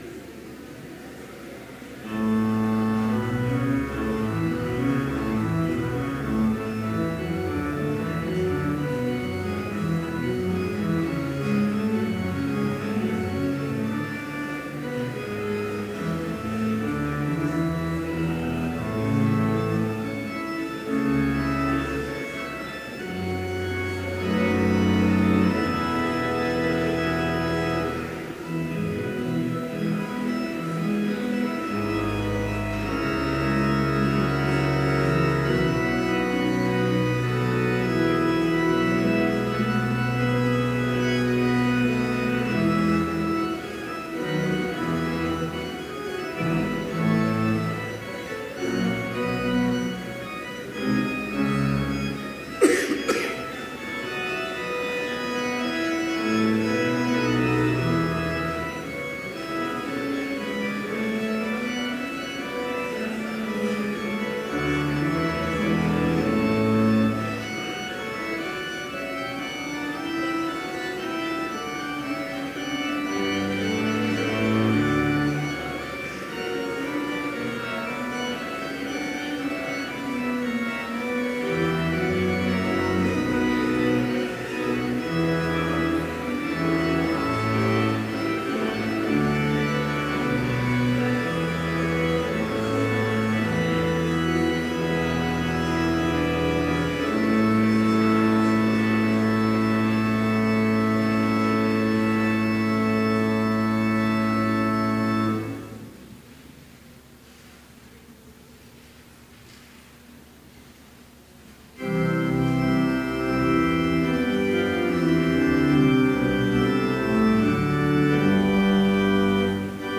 Complete service audio for Chapel - November 27, 2017